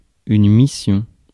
Ääntäminen
Synonyymit tâche destination Ääntäminen France: IPA: /mi.sjɔ̃/ Haettu sana löytyi näillä lähdekielillä: ranska Käännös Ääninäyte Substantiivit 1. mission US Suku: f .